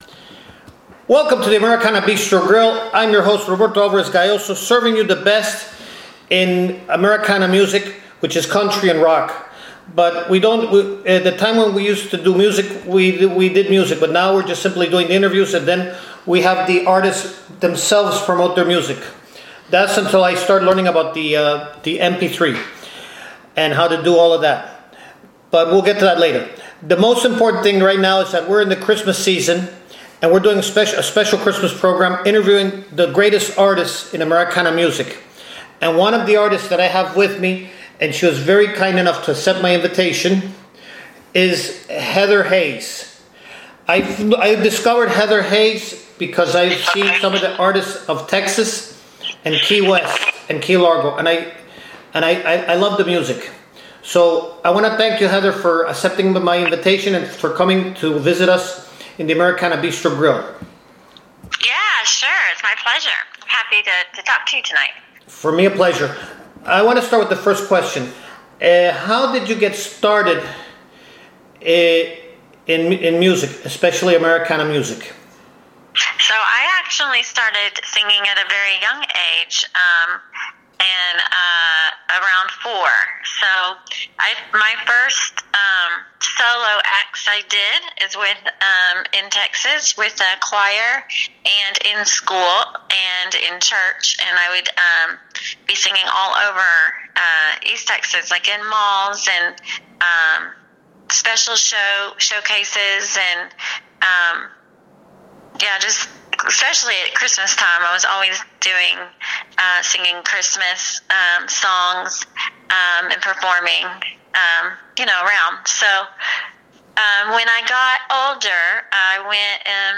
At The Americana Bistro Grill